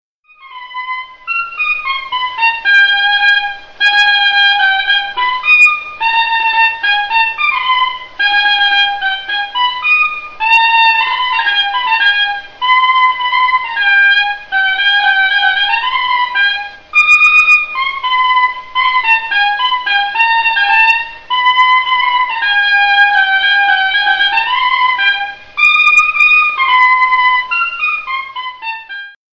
gestuel : à marcher
circonstance : fiançaille, noce
Pièce musicale inédite